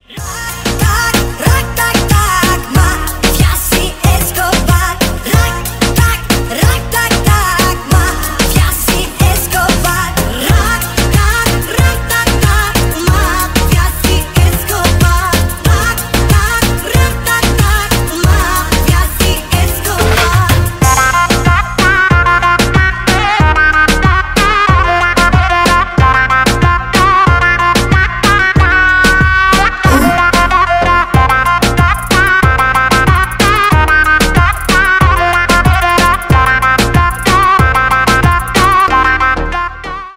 • Качество: 320, Stereo
женский вокал
восточные мотивы
веселые
Крутой трендовый рингтон с Тик Тока в восточном стиле